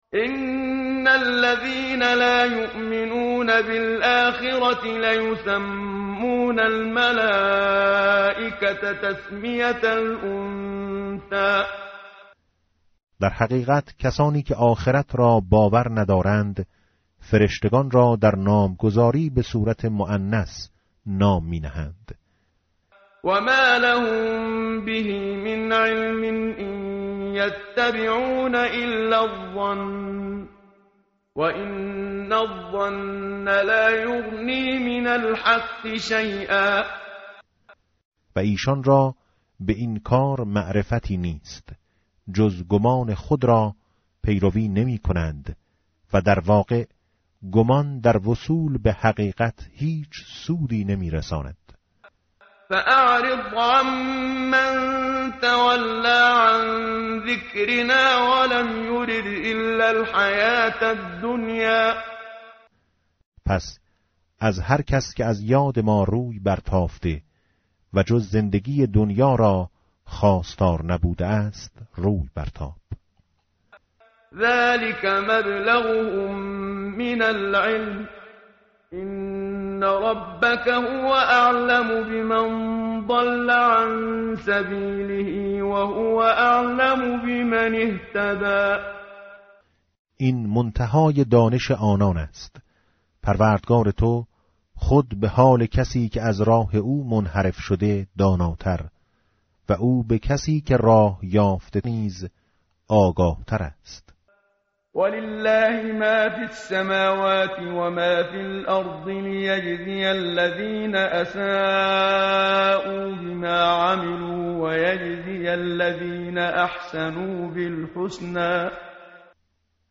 tartil_menshavi va tarjome_Page_527.mp3